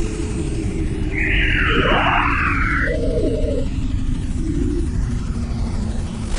Ou des paysages (les images proviennent de Flickr : 1 2), qui ont été traîtés dans Gimp avec les outils de détection de contour (dans ce cas là « Edge »): Paysage traîté avec reconnaissance des bords Fichier OGG (Paysage 1) Un autre paysage traîté avec reconnaissance des bords